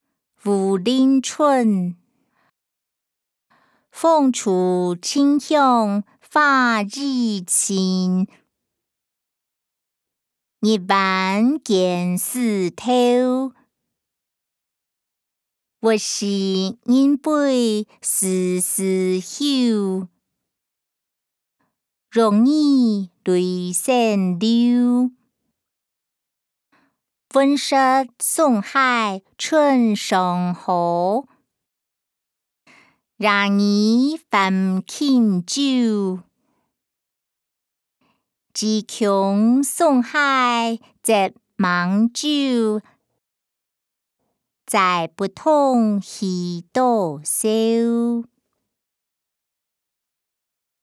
詞、曲-武陵春音檔(海陸腔)